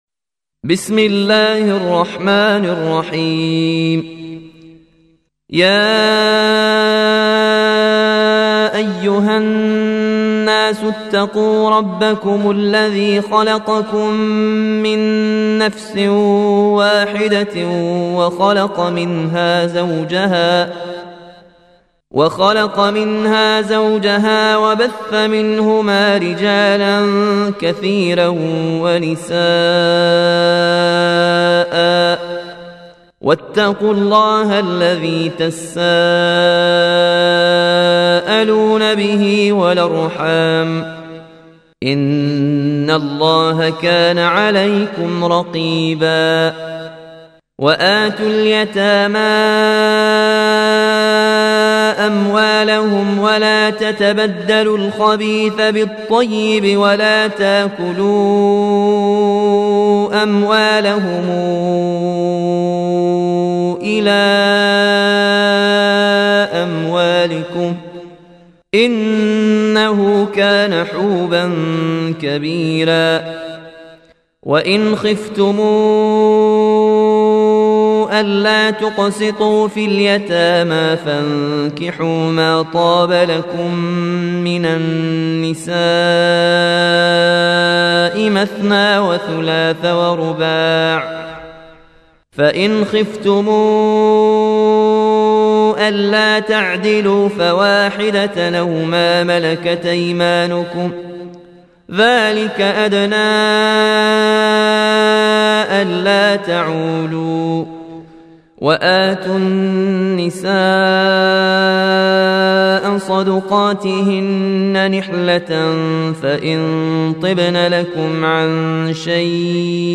Riwayat Warch an Nafi